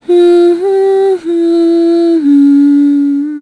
Veronica-Vox_Hum.wav